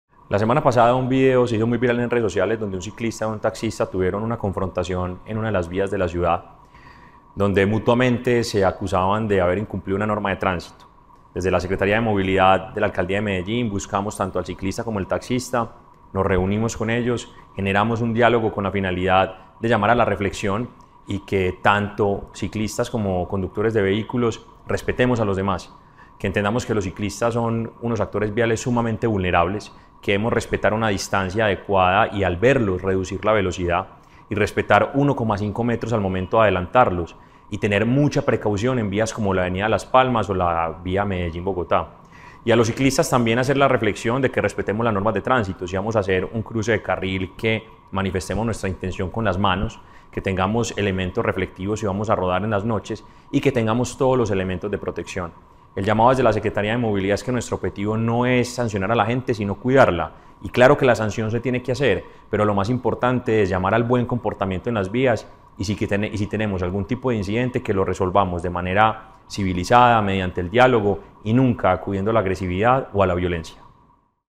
Audio-Declaraciones-del-secretario-de-Movilidad-Mateo-Gonzalez.mp3